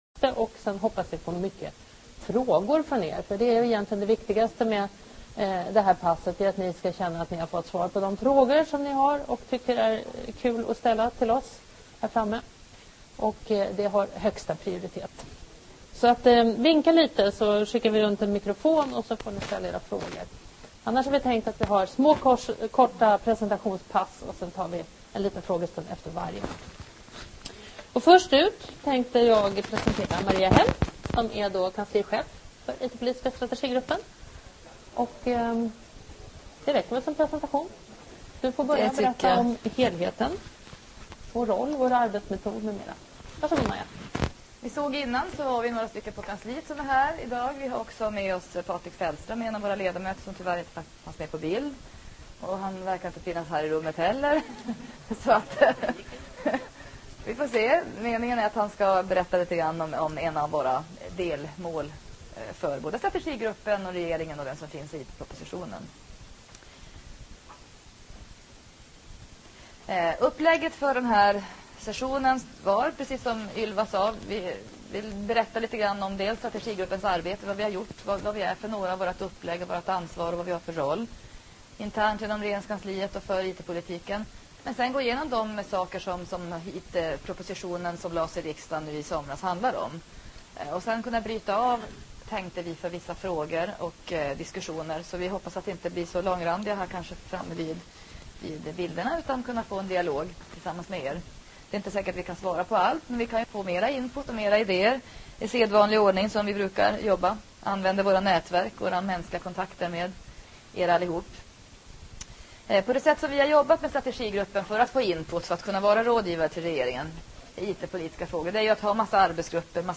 Under seminariet belyses de aspekter av IT-propositionen som har mest betydelse f�r Internet i Sverige och vad den kommer att betyda f�r branschen och f�r enskilda anv�ndare.